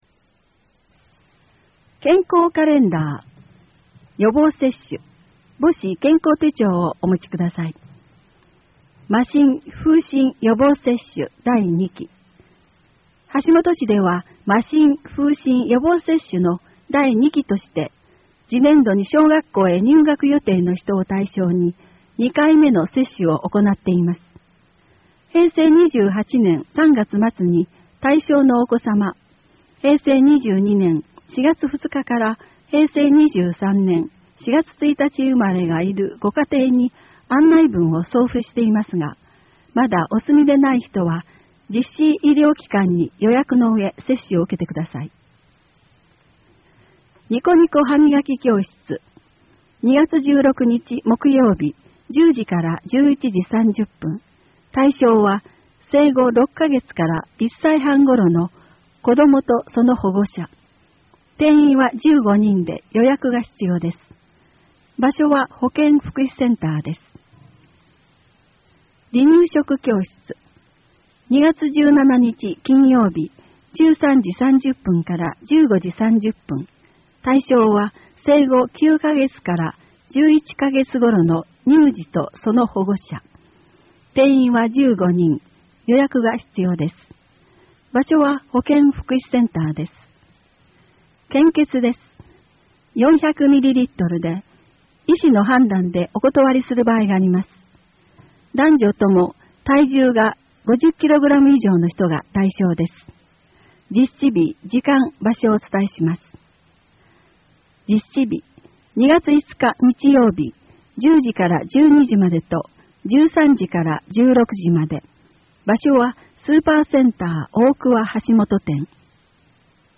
WEB版　声の広報 2017年2月号